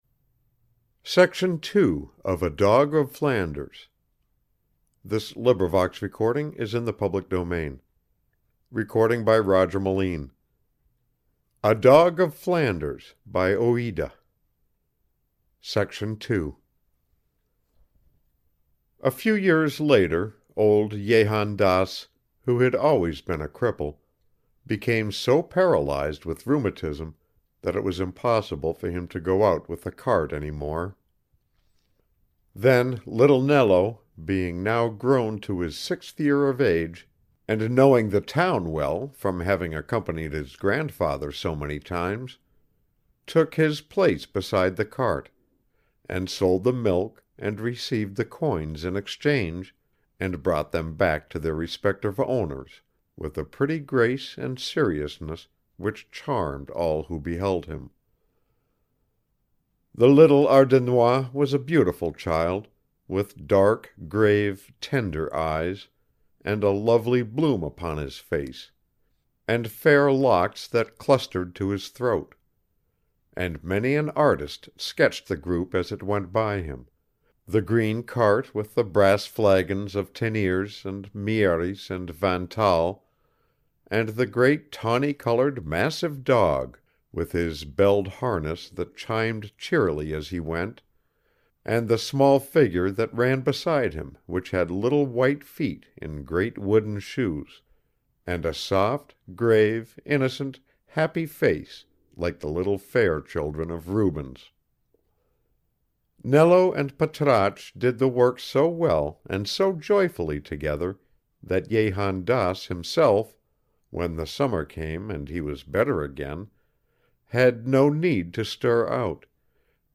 Reading of Dog of Flanders by Marie Louise de la Ramée
84894-reading-of-dog-of-flanders-by-marie-louise-de-la-ramee.mp3